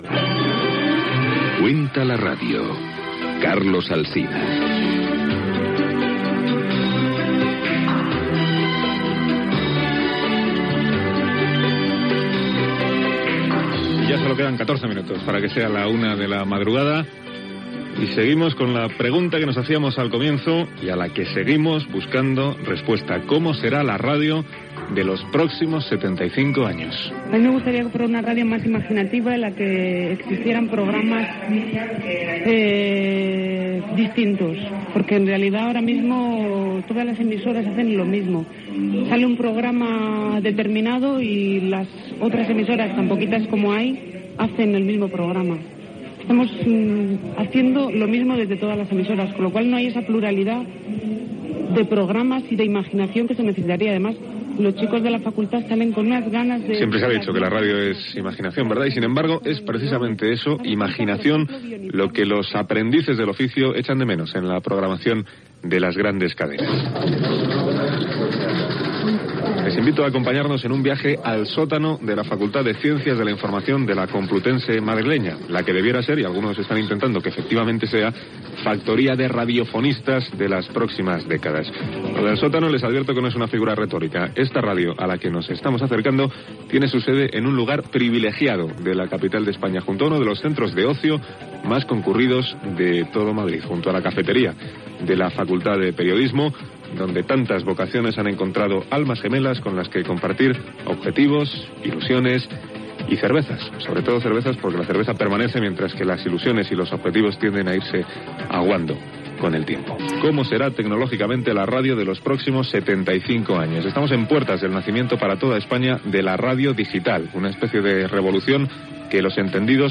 Espai fet des de la Facultat de Periodisme de la Univerdidad Complutense de Madrid. Com serà la ràdio dels propers 75 anys.